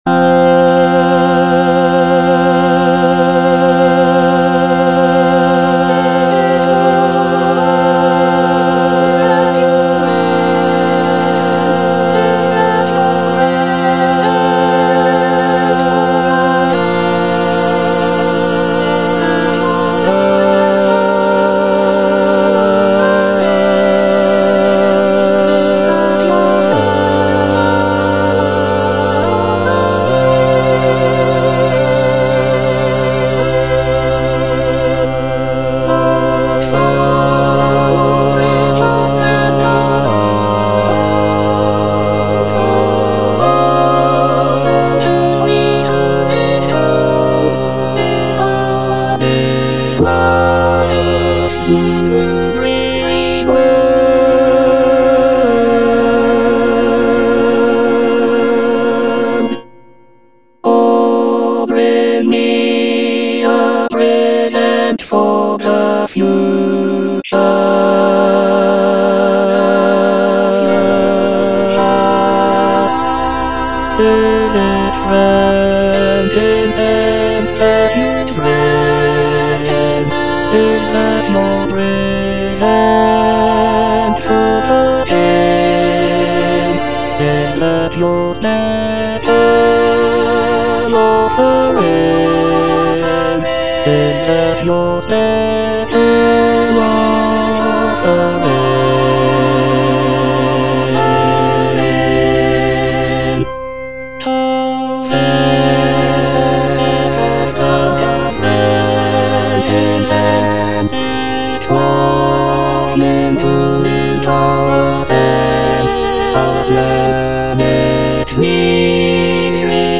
Bass (Emphasised voice and other voices)